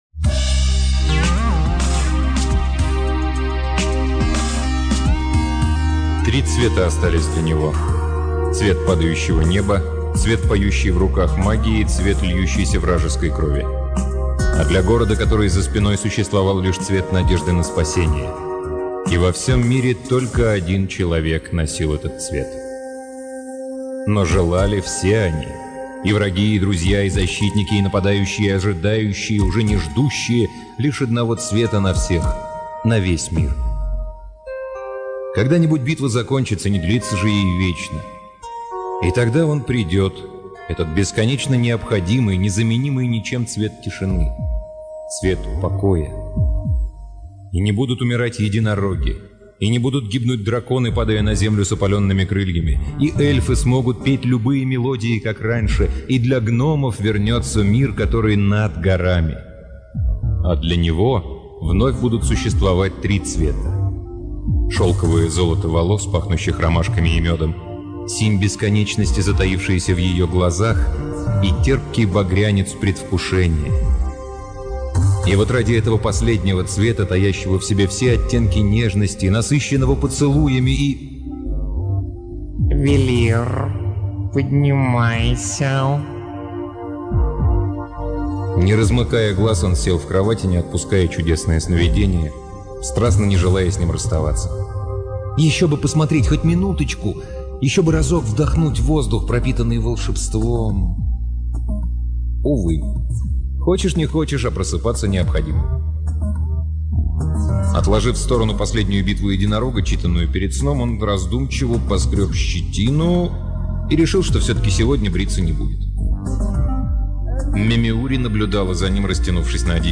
Аудиокнига Сергей Вольнов — Желанная